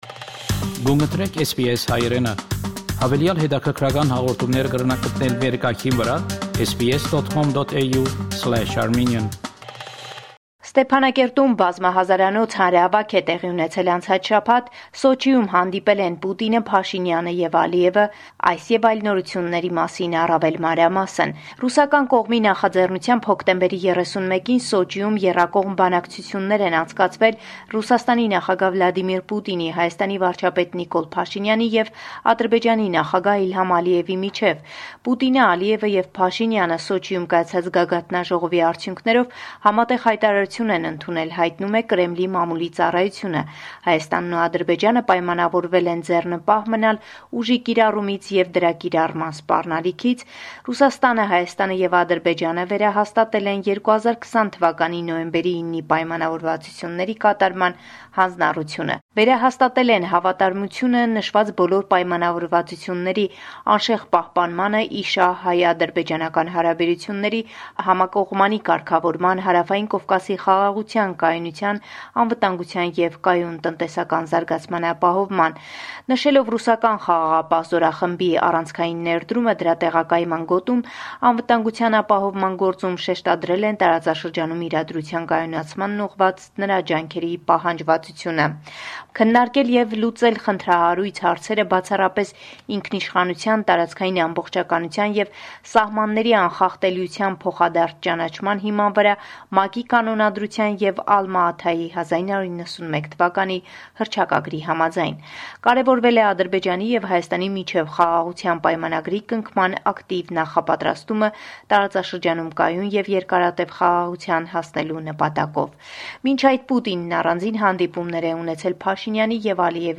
News from Armenia, Artsakh and the Diaspora from our reporter